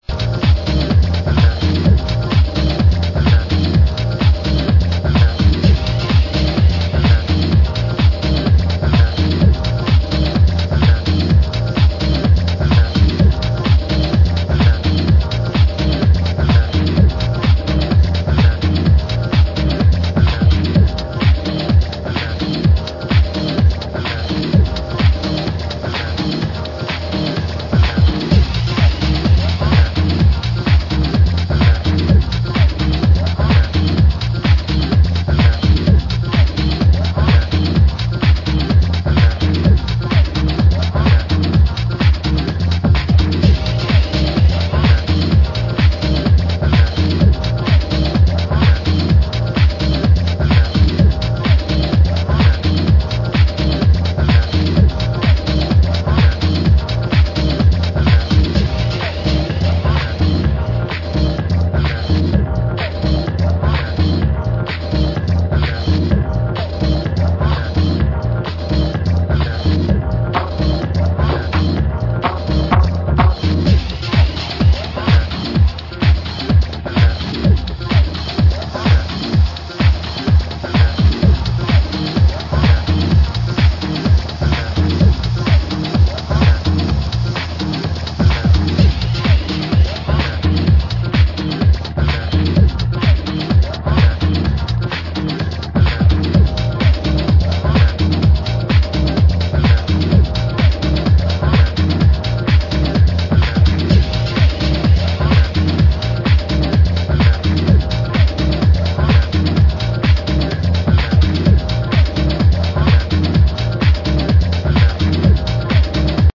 deeper house grooves and hypnotic rhythms